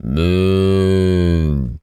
cow_moo_05.wav